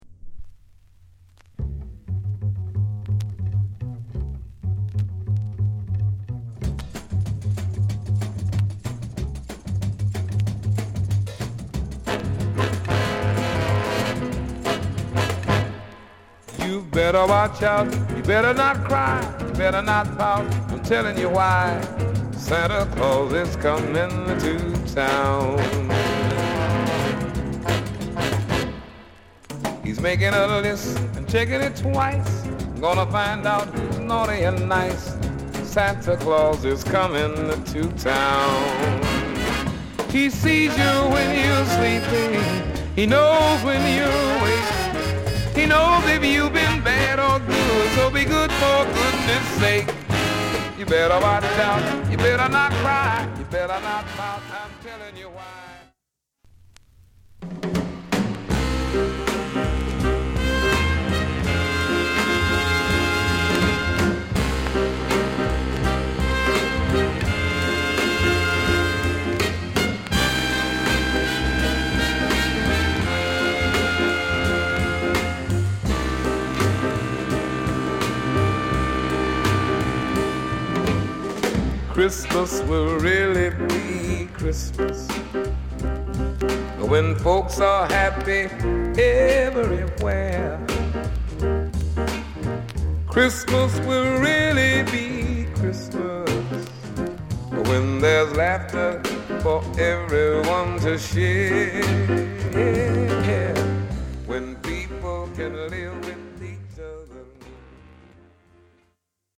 カッコ良いベースソロから始まりブラスもファンキーな